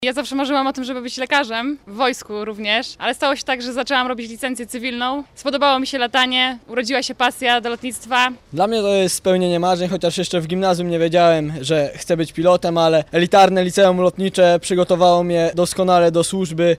– Marzenia się spełniają – mówią nowi podchorążowie o studiach w legendarnej uczelni…
mowia_nowi_podchorazowie.mp3